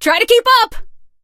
max_kill_vo_02.ogg